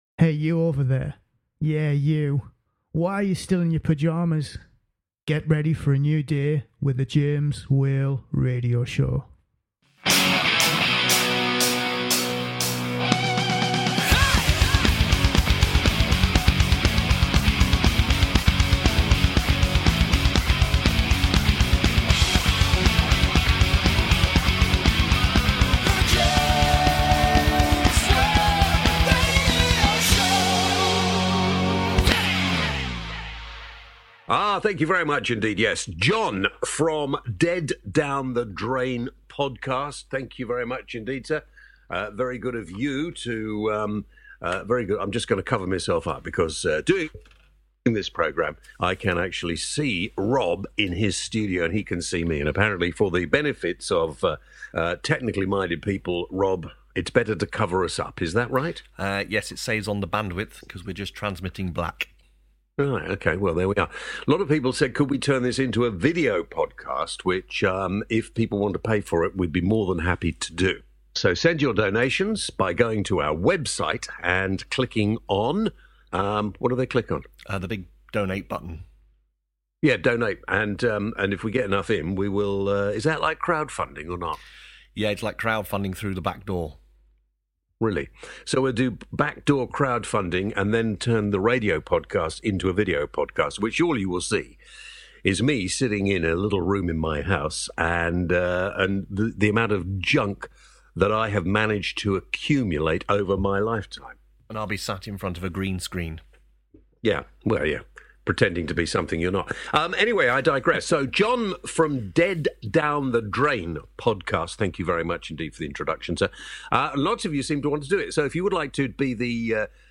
On this weeks James Whale Radio Show, James asks Whats the point of a Scottish referendum, Should headscarves be banned in the workplace? Also on the show: Whales Mail, Tech Talk, and Your questions.